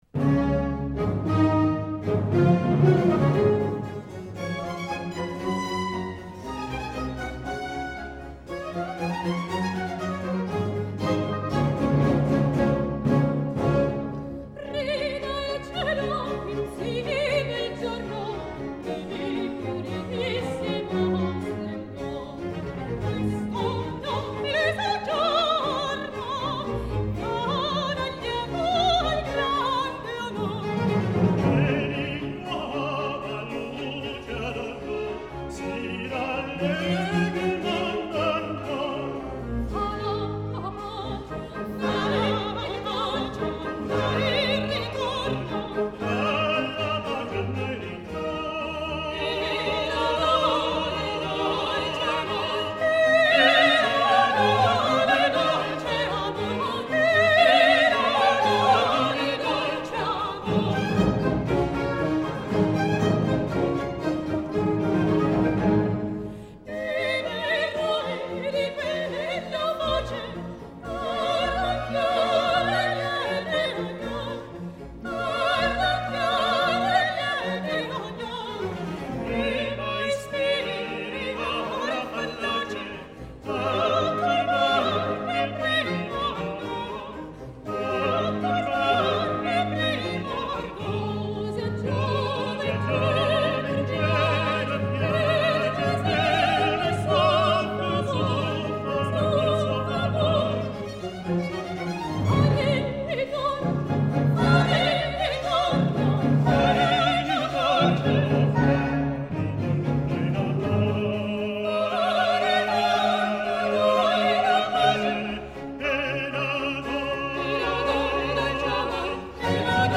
Registrazione live - Prima registrazione mondiale
Coro finale